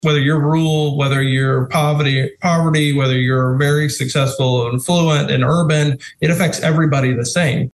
CLICK HERE to listen to details from Oklahoma’s Career Tech’s State Director, Brent Haken.